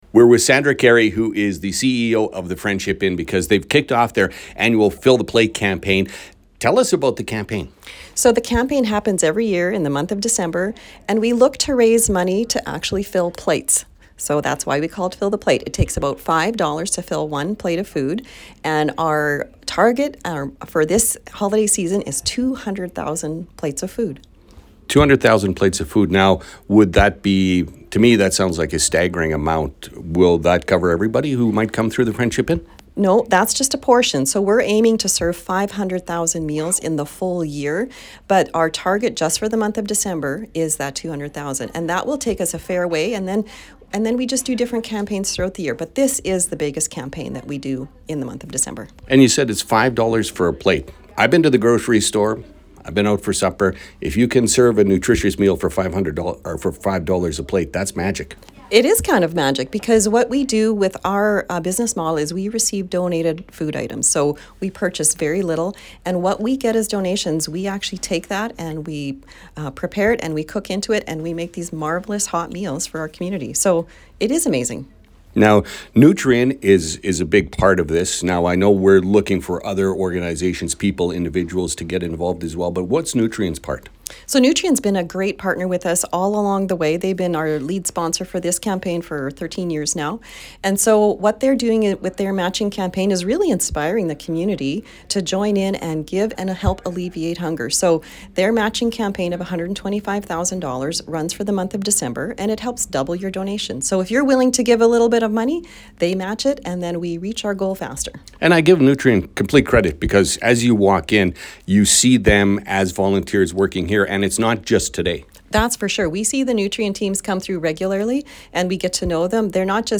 to talk about the campaign and the need: